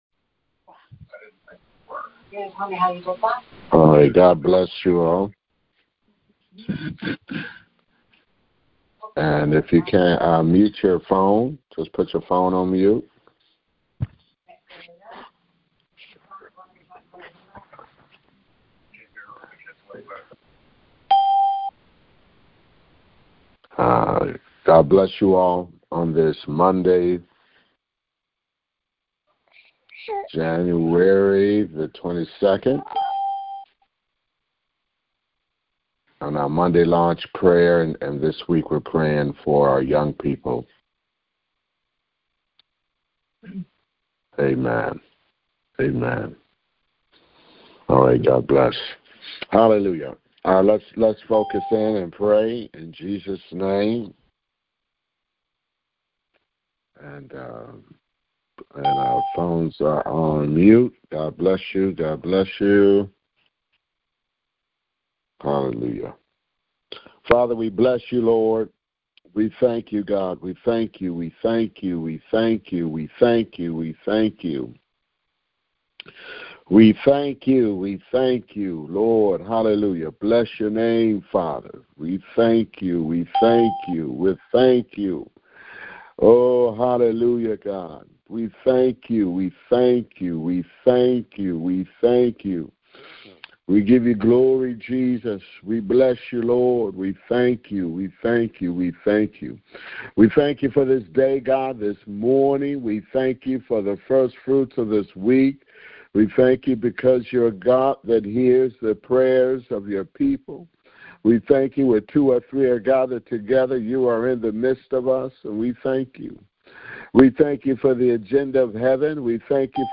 Prayers for children and teens taken from the weekly prayer conference line.